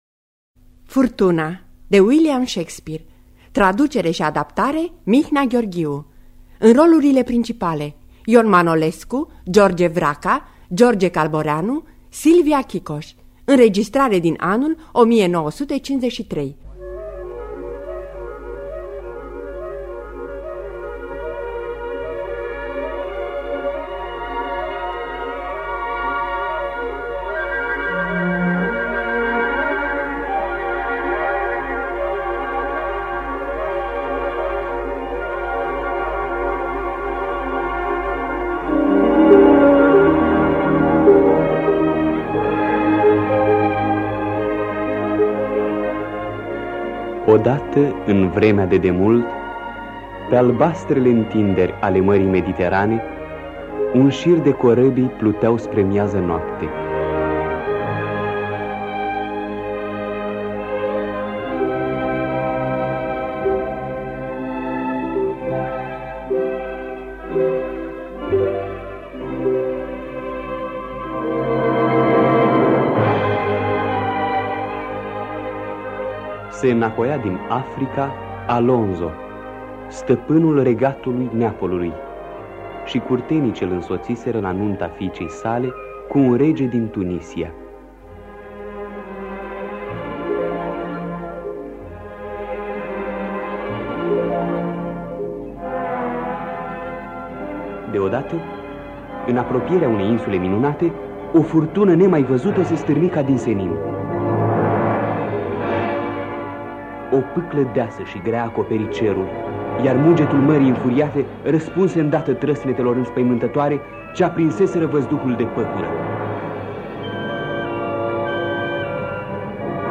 Traducerea şi adaptarea radiofonică de Mihnea Gheorghiu.